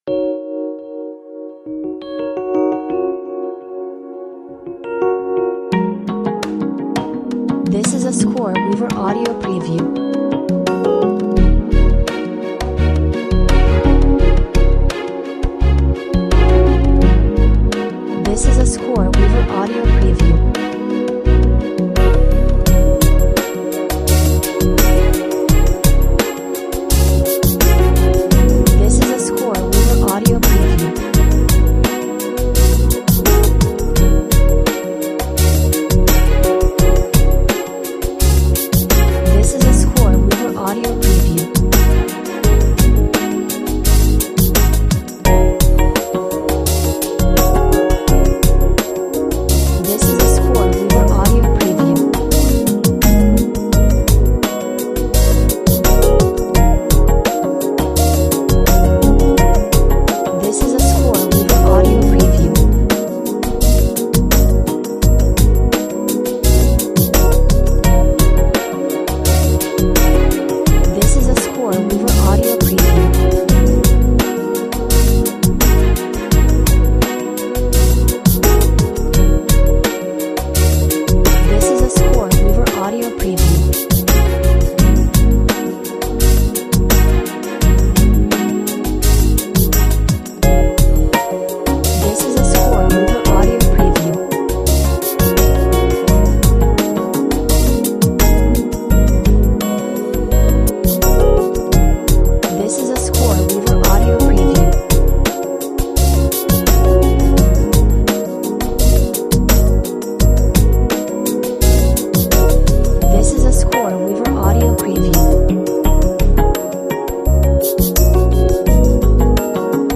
Relaxed, reflective and laid back.